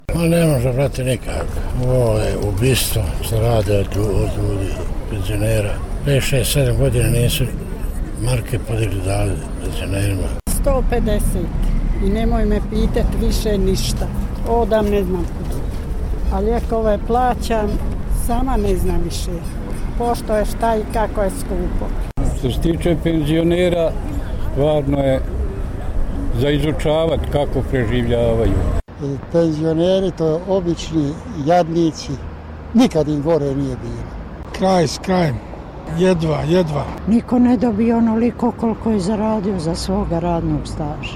Dovoljno je čuti i odgovore na ulicama Banjaluke i Sarajeva kada smo penzionere pitali da li im je penzija, veličine nekoliko dnevnica naših političara, dovoljna da prežive mjesec dana: